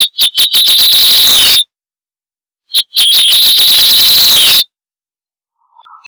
Anumbius annumbi - Espinero